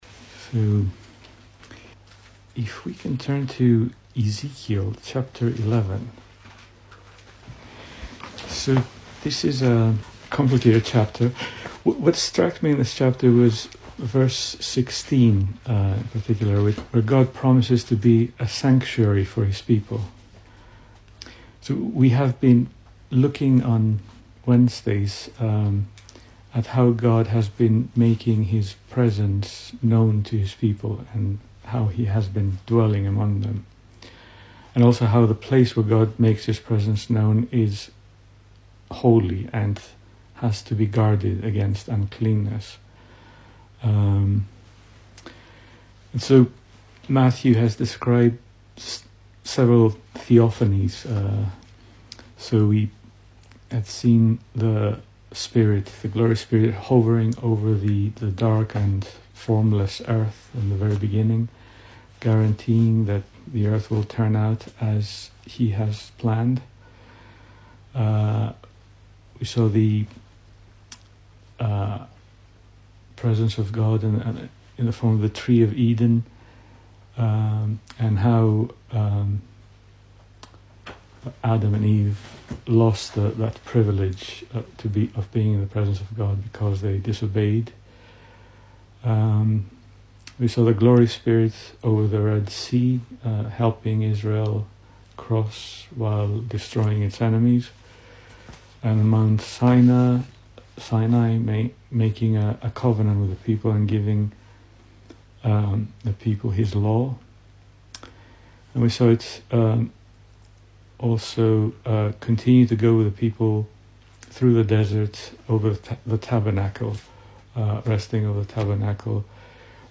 Service Type: Weekday Evening
Series: Single Sermons